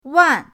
wan4.mp3